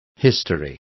Complete with pronunciation of the translation of history.